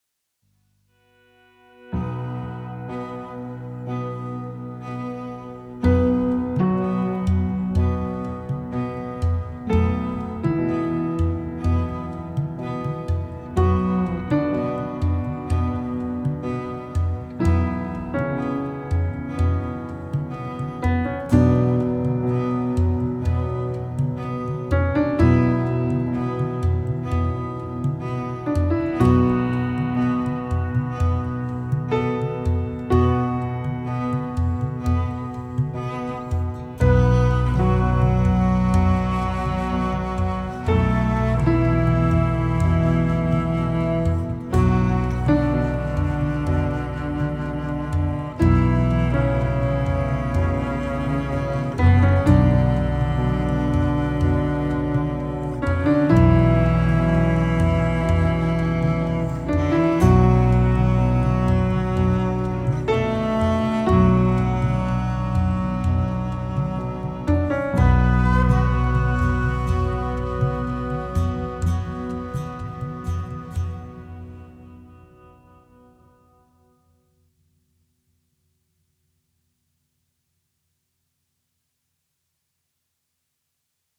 cello
flute, saxophone
percussion
guitars
piano, accordion, cimbalom